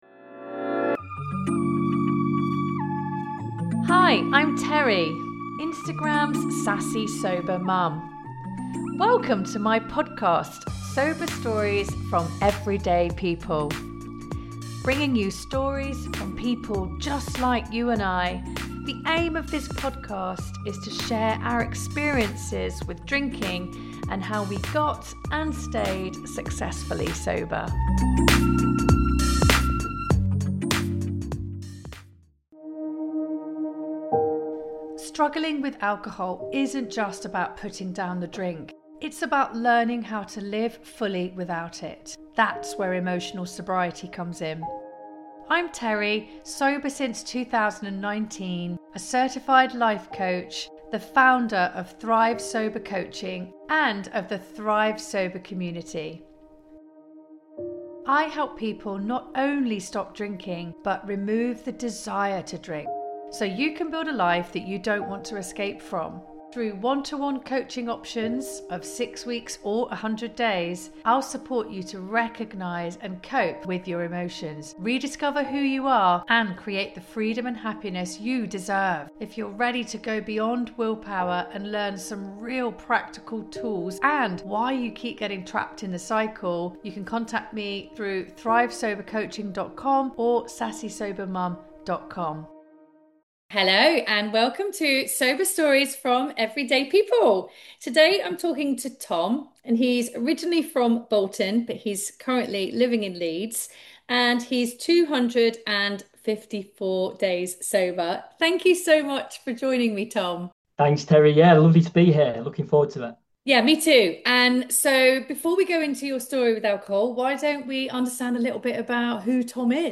guests will answer the same seven questions, each outlining their struggle with alcohol and journey into successful sober living.